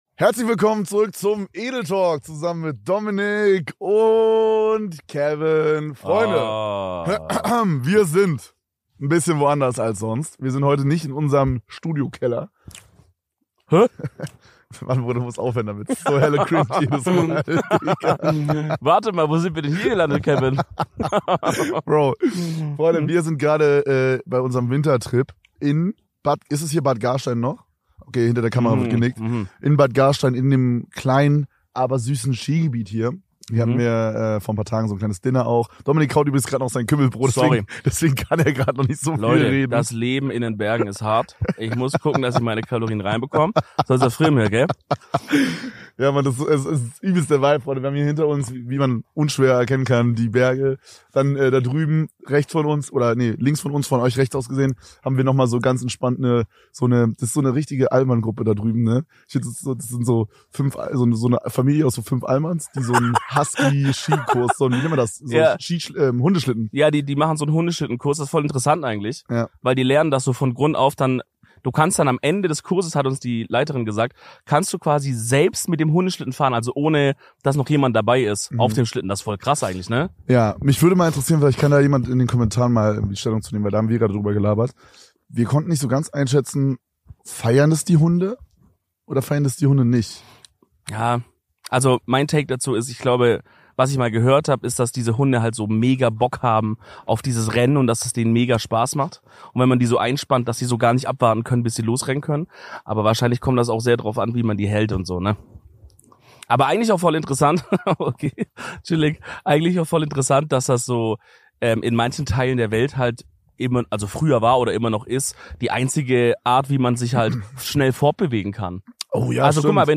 Heute präsentieren wir euch im Rahmen unseres Red Bull Winterurlaubs eine kalte Ausgabe vom Edeltalk aus den österreichischen Bergen.